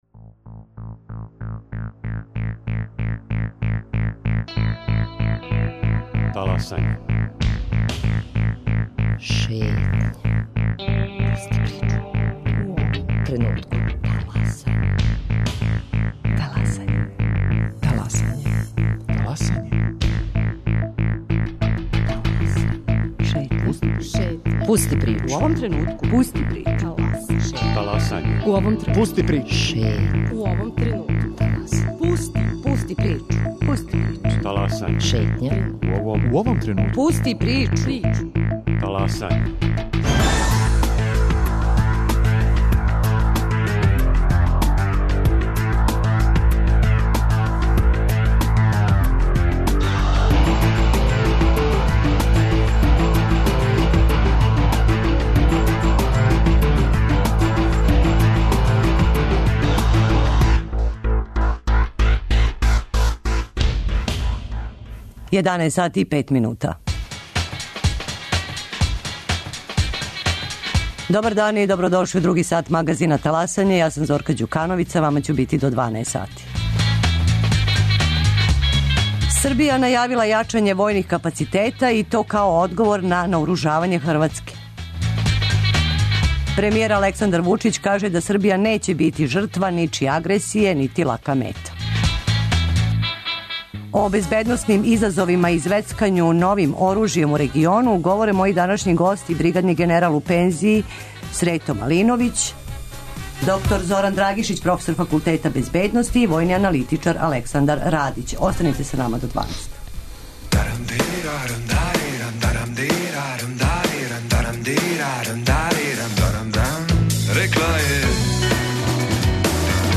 О безбедносним изазовима и звецкању новим оружјем у региону говоре гости